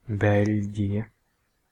Ääntäminen
Synonyymit Kingdom of Belgium Ääntäminen UK : IPA : [ˈbɛl.dʒəm] US : IPA : [ˈbɛl.dʒəm] Lyhenteet ja supistumat (laki) Belg.